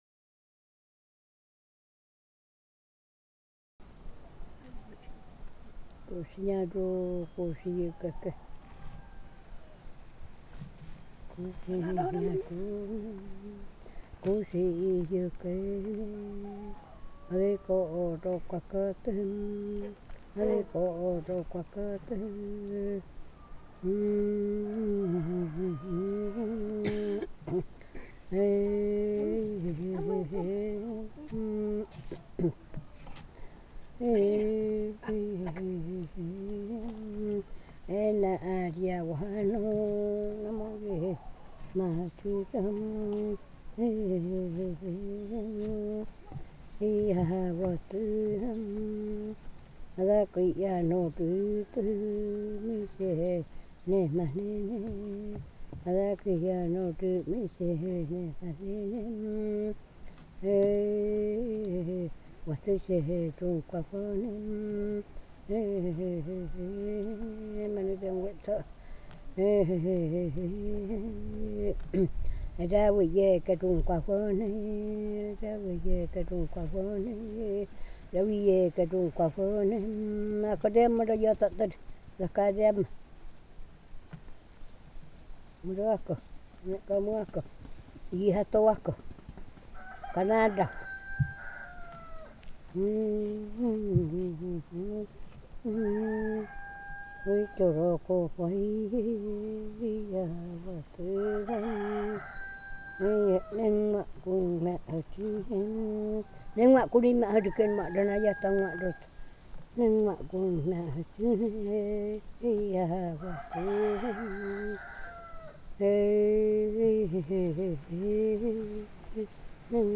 Povo Ye'kwana
canto
executado durante o ritual do fim do resguardo da menina moça